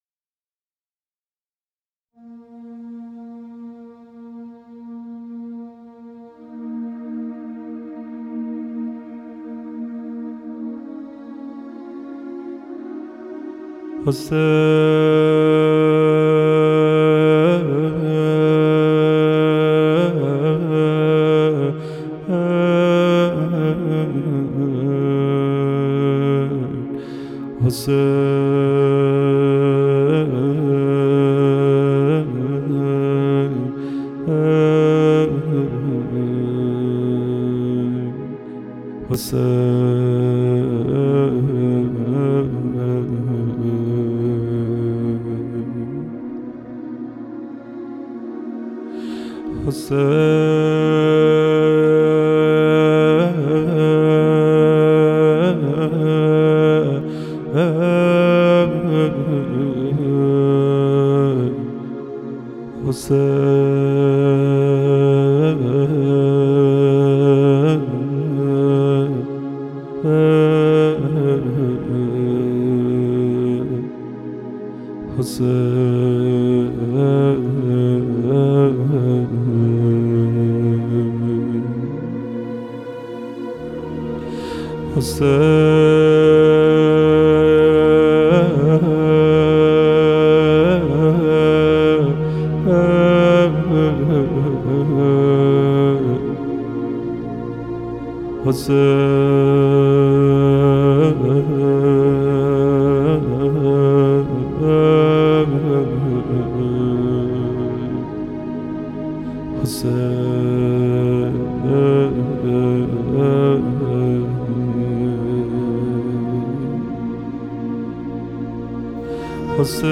ذکر اهل بیت